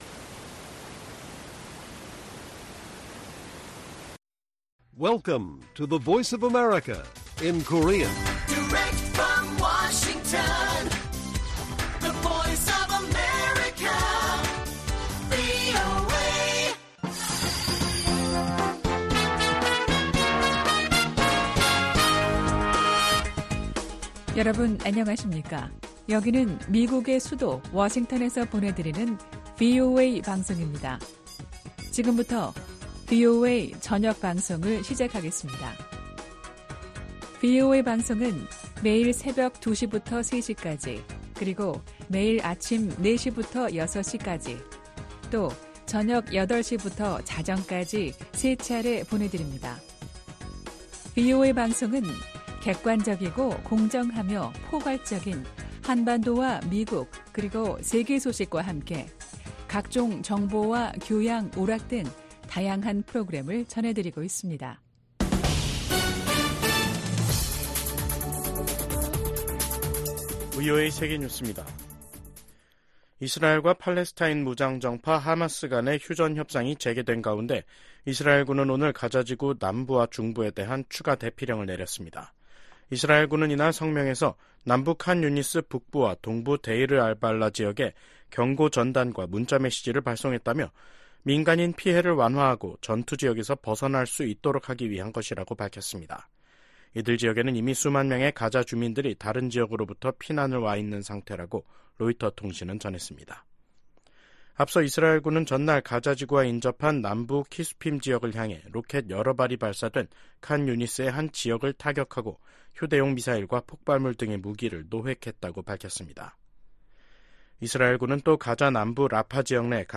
VOA 한국어 간판 뉴스 프로그램 '뉴스 투데이', 2024년 8월 16일 1부 방송입니다. 미국 정부가 일본 고위 당국자들의 야스쿠니 신사 참배를 “과거 지향적”이라고 평가했습니다. 백악관 당국자가 연내 미한일 3국 정상회담 개최 가능성을 거론했습니다. 윤석열 한국 대통령이 발표한 자유에 기반한 남북 통일 구상과 전략에 대해 미국 전문가들은 “미래 비전 제시”라고 평가했습니다.